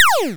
laser_01.wav